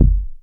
GIGA C2.wav